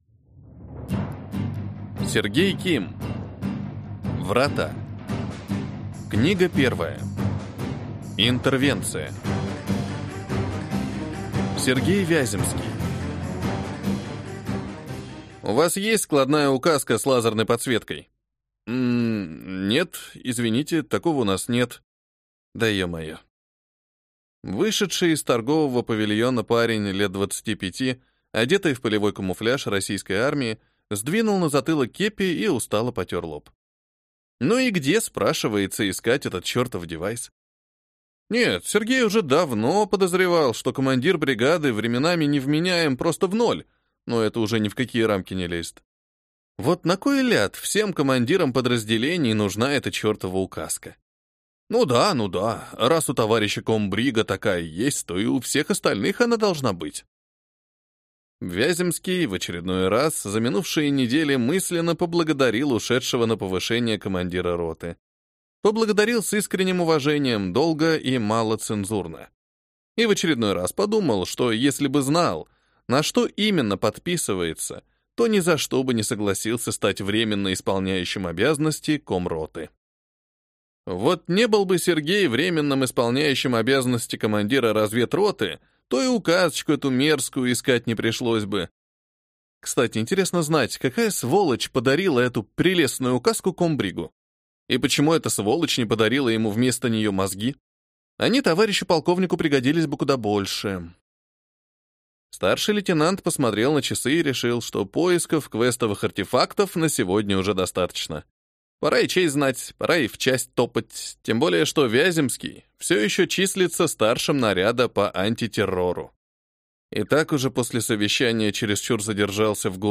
Аудиокнига Врата. Книга 1. Интервенция | Библиотека аудиокниг